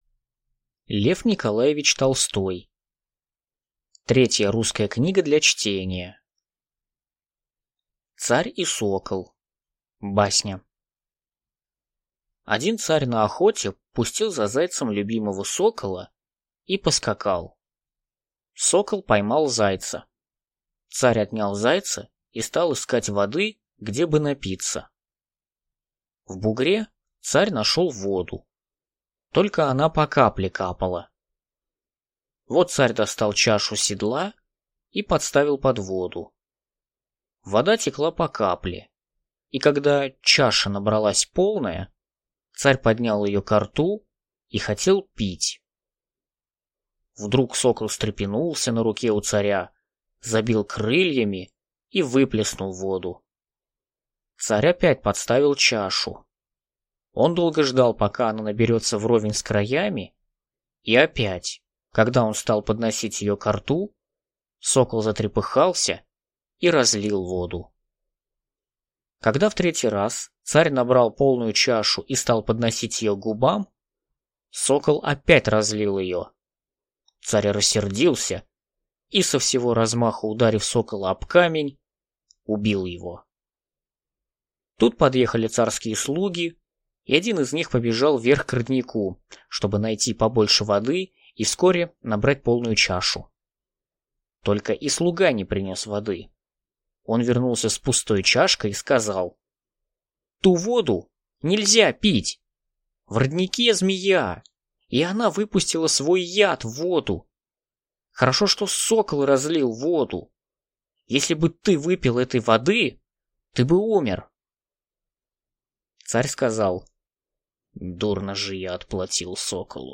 Аудиокнига Третья русская книга для чтения | Библиотека аудиокниг